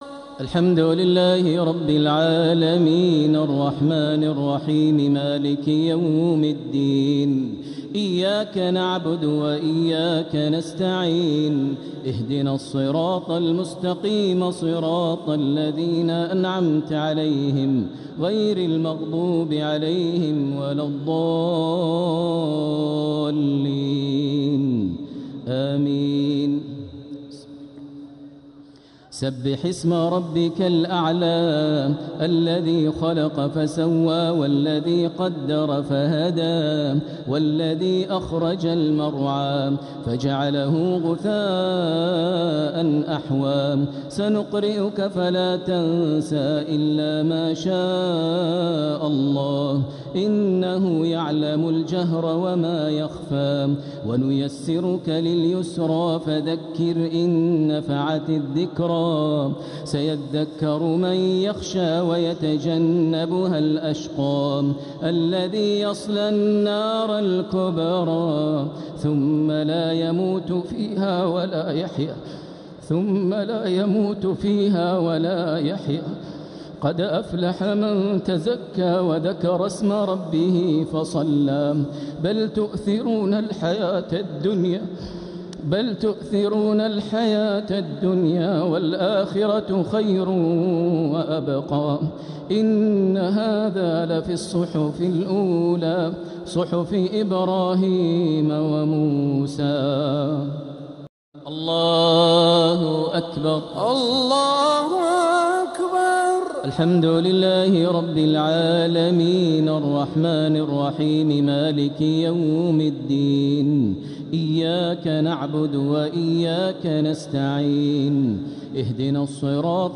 صلاة الشفع و الوتر ليلة 3 رمضان 1447هـ | Witr 3rd night Ramadan 1447H > تراويح الحرم المكي عام 1447 🕋 > التراويح - تلاوات الحرمين